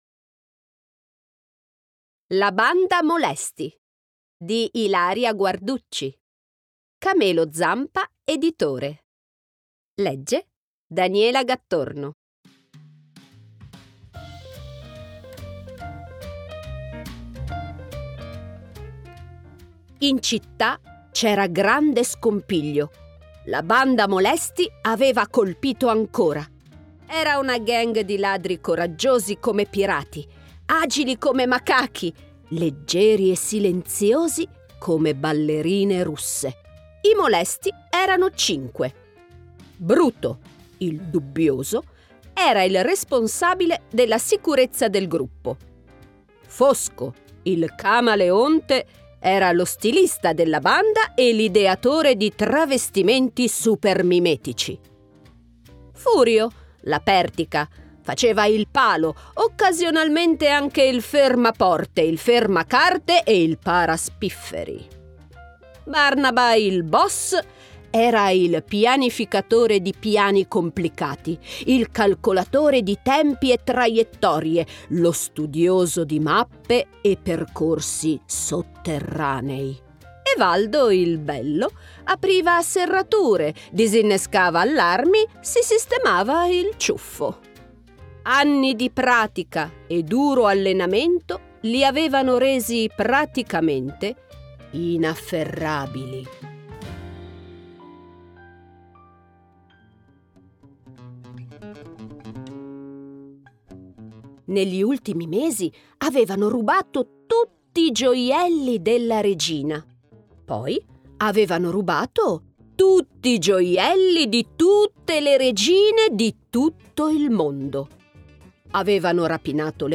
- La banda molesti - Audiolibro con tappeto sonoro